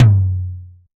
DX tom low.wav